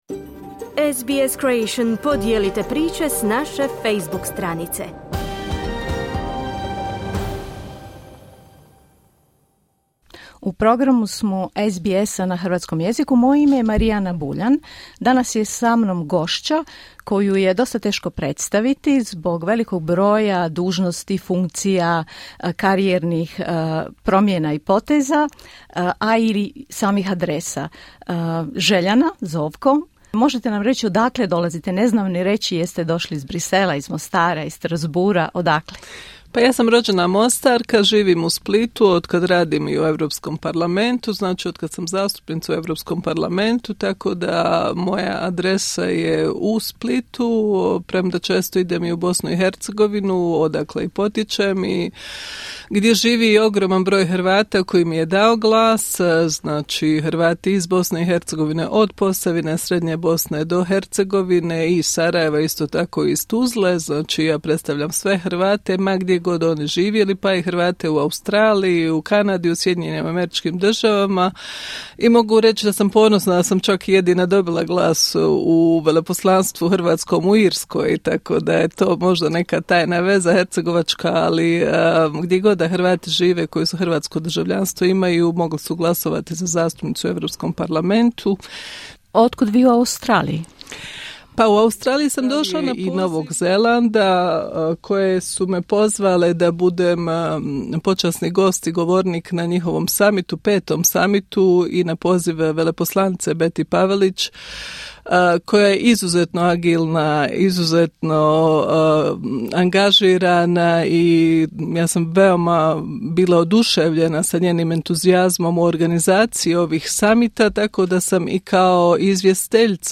Željana Zovko, zastupnica HDZ-a u Europskom parlamentu u studiju SBS-a, Melbourne, studeni 2025.